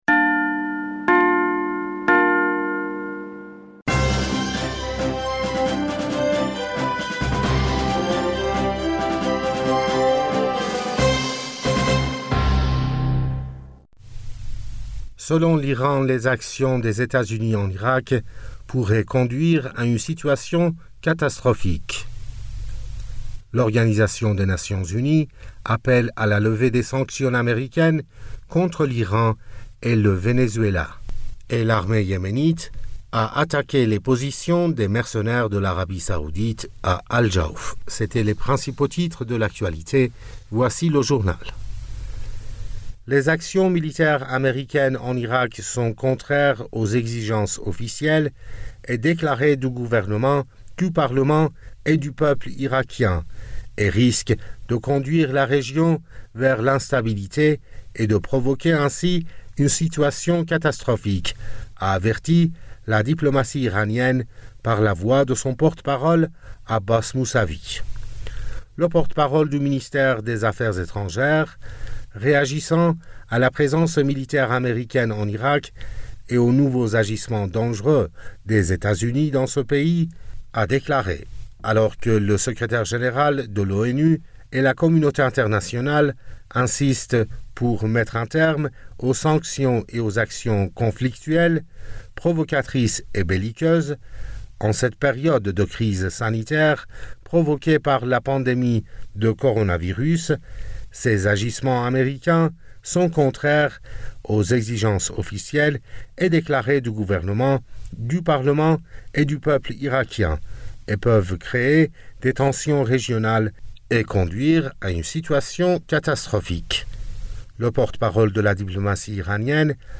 Bulletin d'information du 02 avril 2020